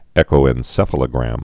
(ĕkō-ĕn-sĕfə-lə-grăm, -ə-lō-)